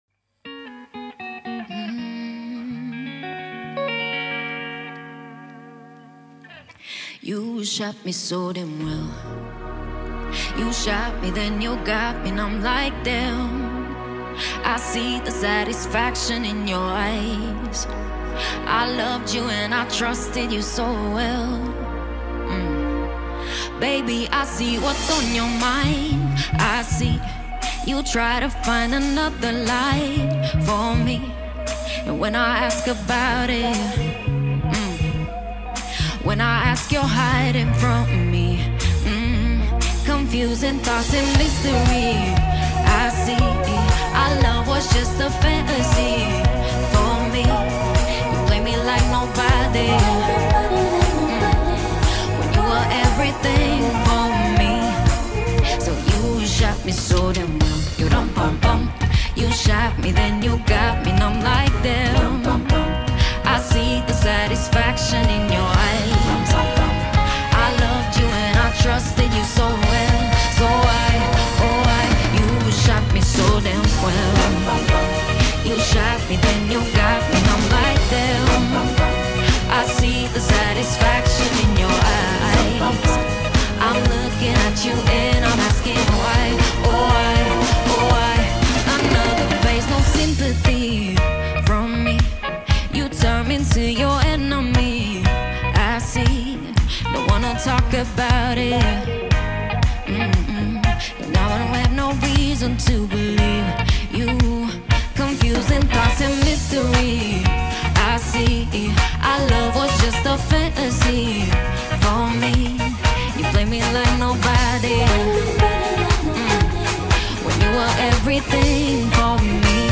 اجرای زنده